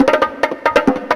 02_01_drumbreak.wav